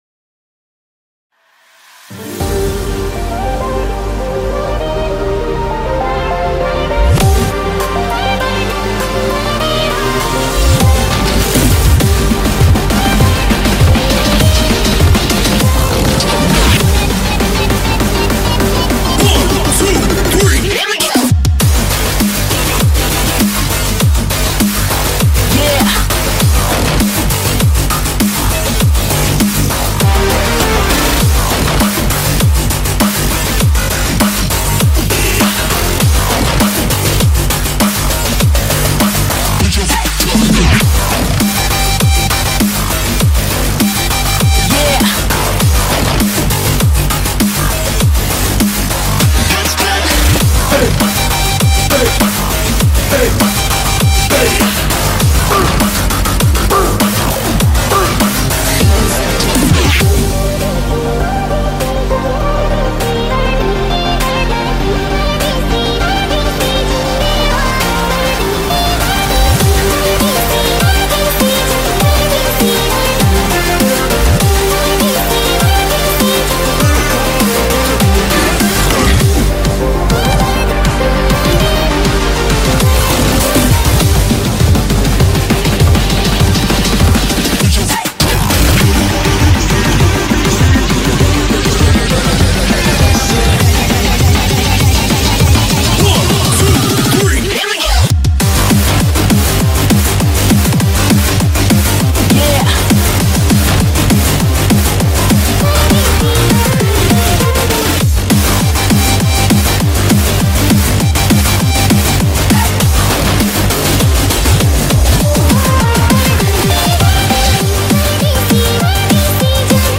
BPM100-200